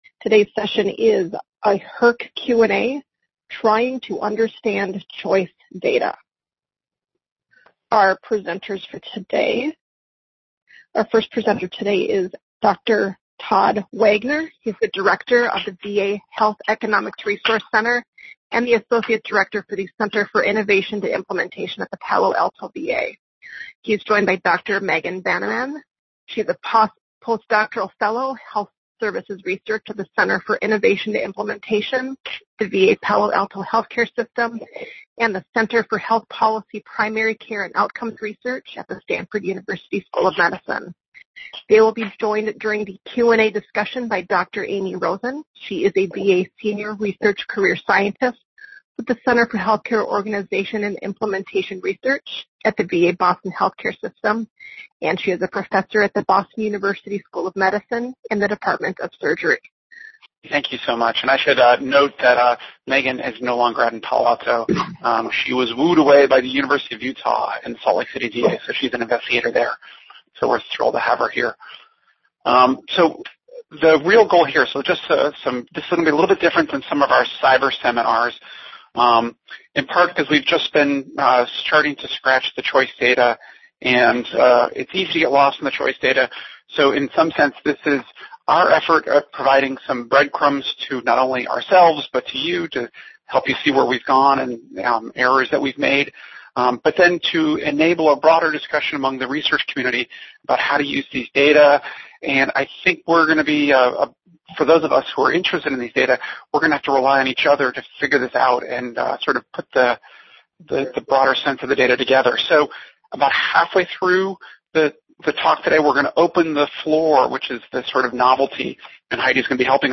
HERC Health Economics Seminar